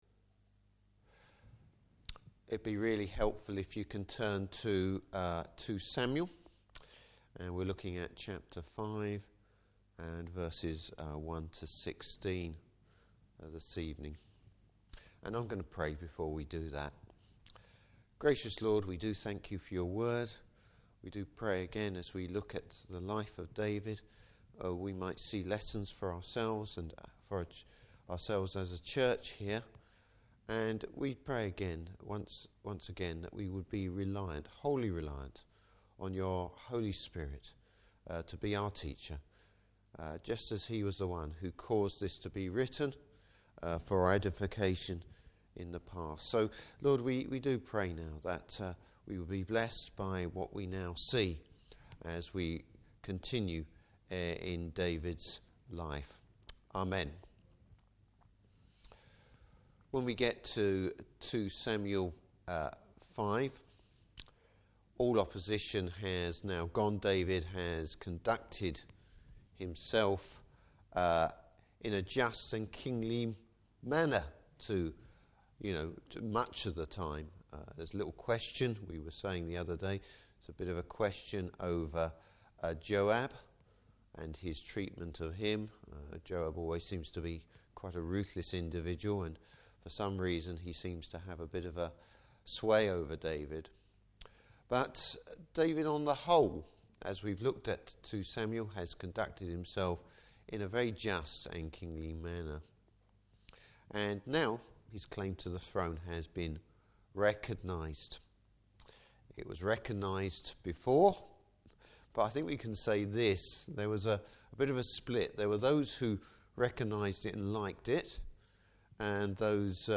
Service Type: Evening Service David seeks to unify the nation.